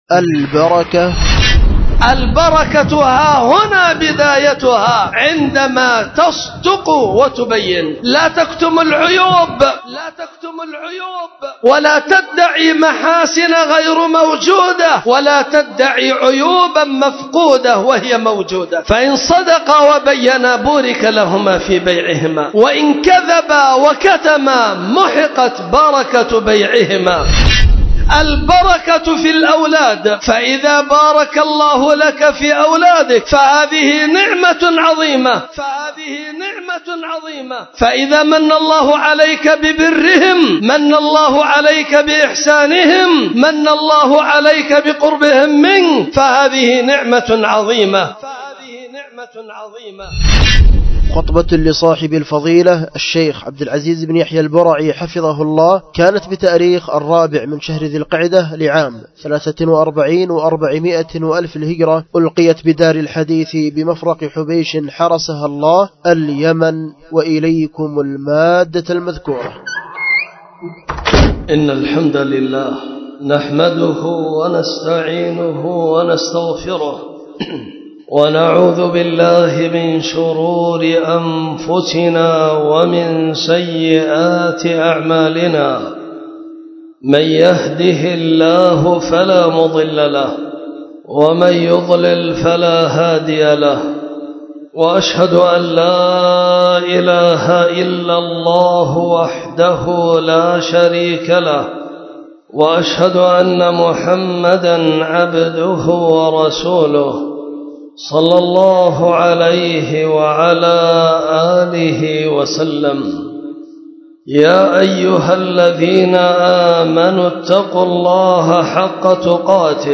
خطبة
ألقيت بدار الحديث بمفرق حبيش